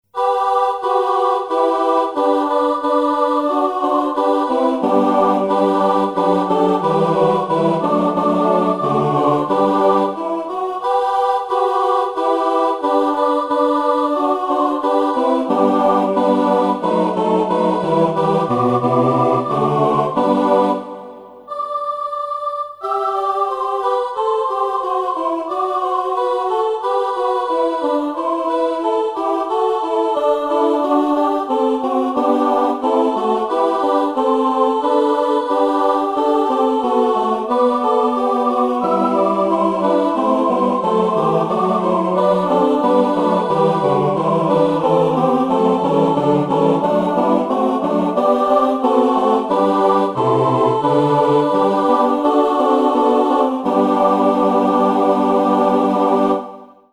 Ensuite, c'est l'époque qui veut ça, un petit "cantique" de circonstance :